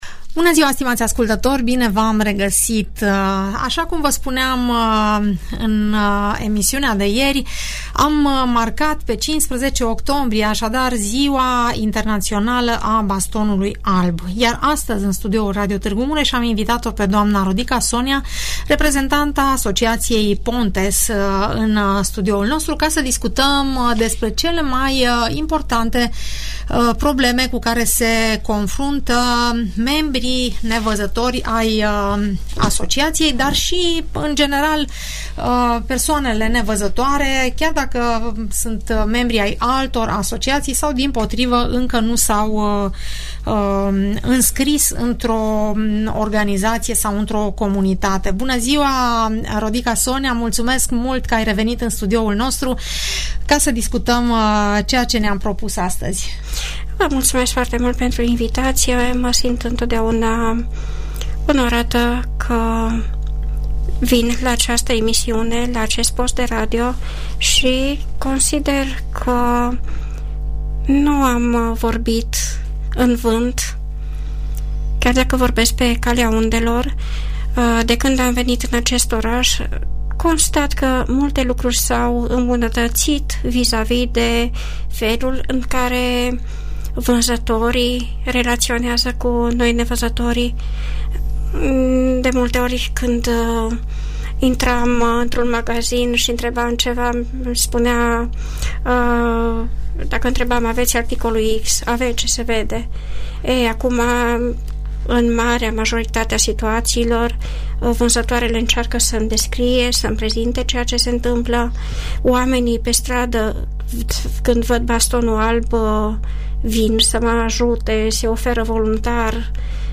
discută în emisiunea „Părerea ta” de la Radio Tg. Mureș